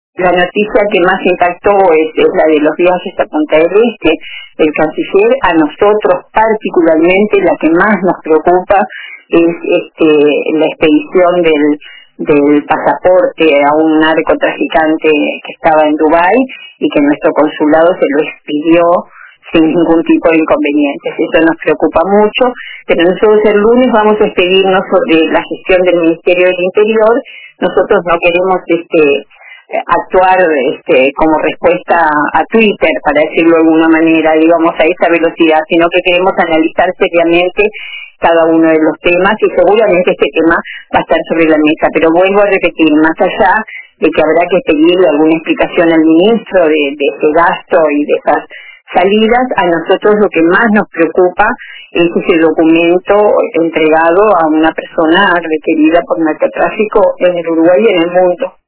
La senadora frenteamplista y ex ministra de Turismo, Liliam Kechichian, participó este viernes en LA TARDE DE RBC